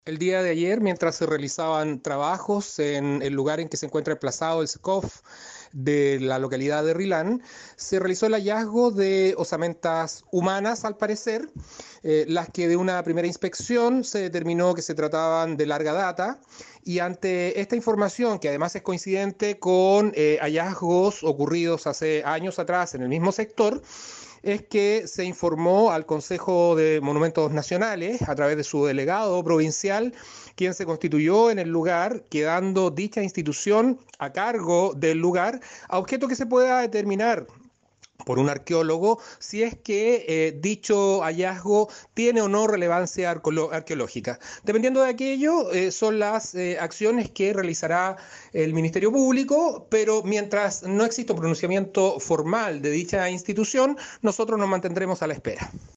El fiscal del ministerio Público Enrique Canales expresó que el hecho fue comunicado al Consejo de Monumentos Nacionales, el que deberá revelar si estos restos tienen importancia arqueológica.